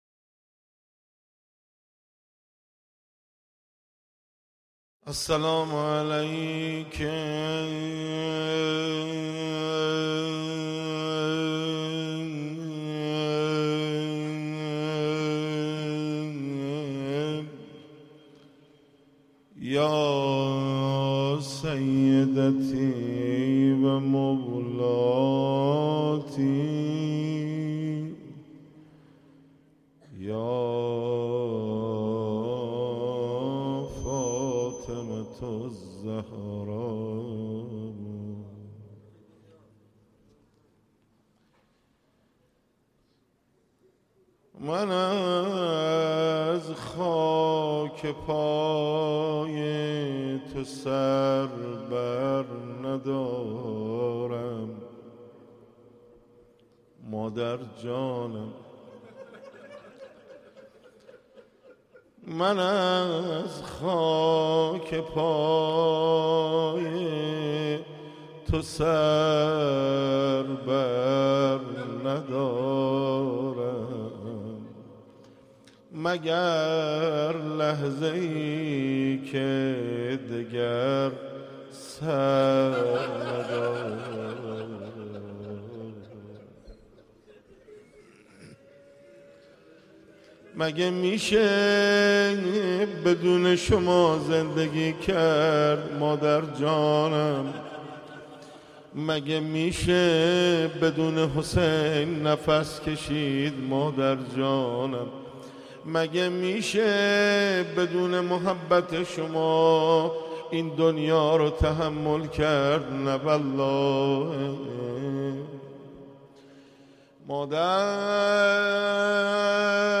11 اسفند 95 - حسینیه تاج دارباهو - روضه - من از خاک پای تو